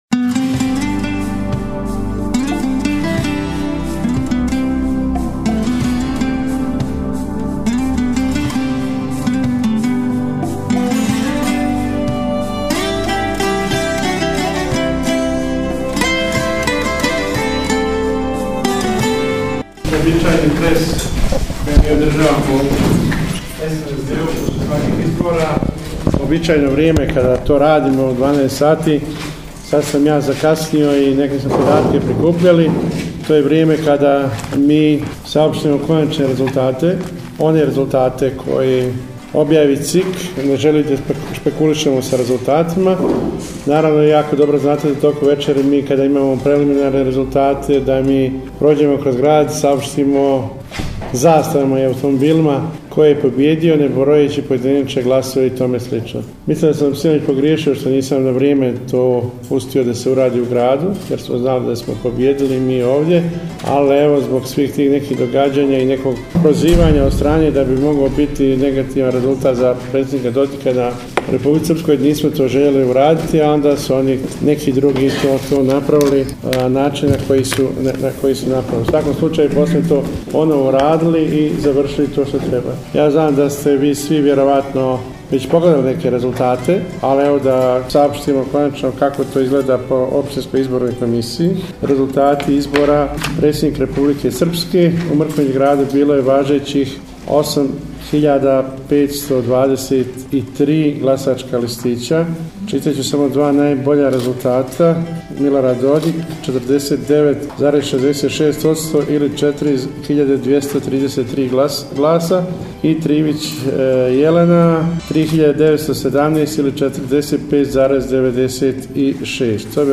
Predsjednik Opštinskog odbora SNSD-a Mrkonjić Grad Zoran Tegeltija održao je u ponedjeljak, 3. oktobra 2022. godine konferenciju za medije o ostvarenim rezultatima na izborima.